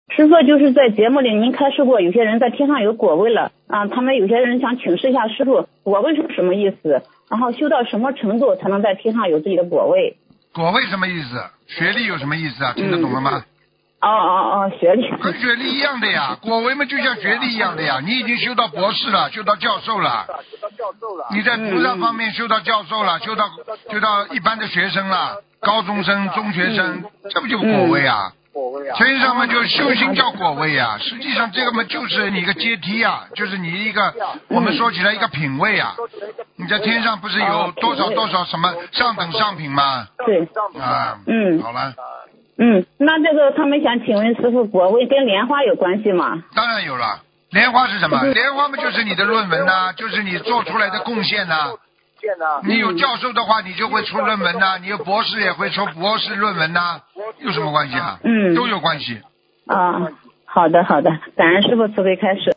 但因对话交流带有语气，文字整理不可能完全还原情境。